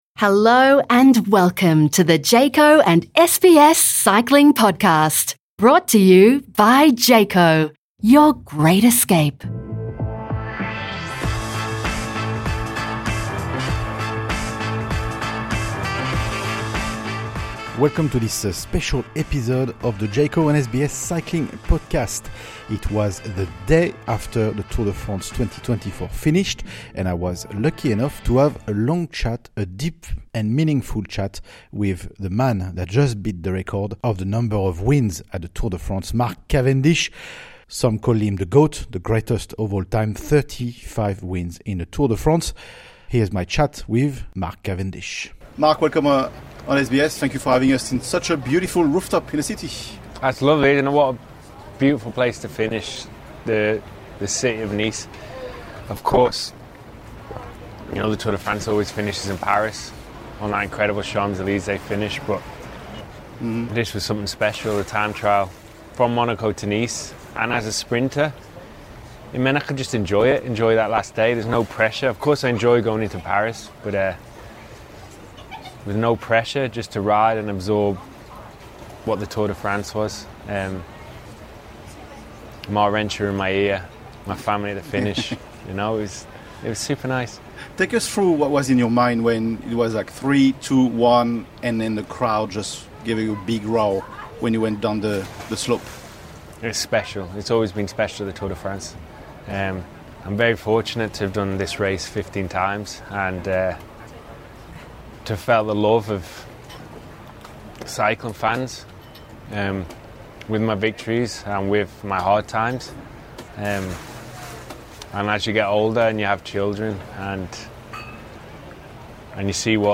Exclusive Interview - Mark Cavendish : 'If I inspired one child, then my job is done'